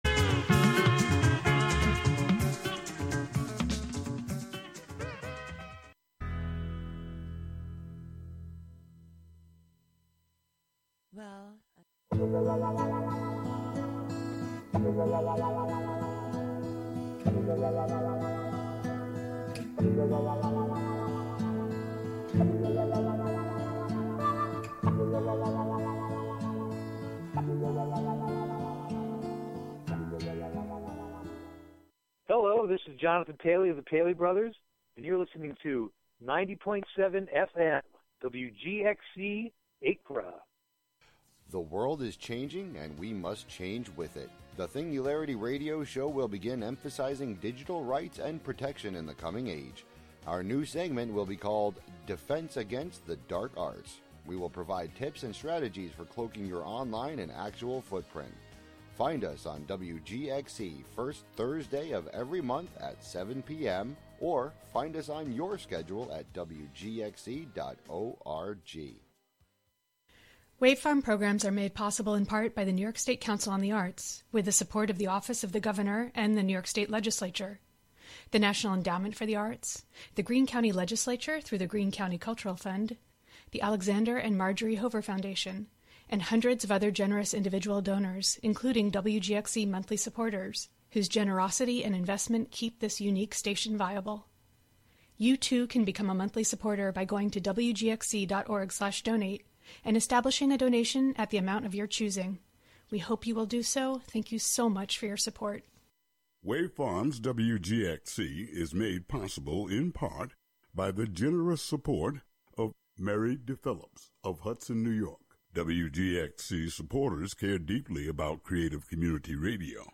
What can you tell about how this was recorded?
"The Conduction Series" is a collaborative live radio broadcast produced by sound and transmission artists across the Americas on Wave Farm’s WGXC 90.7-FM Radio for Open Ears in New York’s Upper Hudson Valley.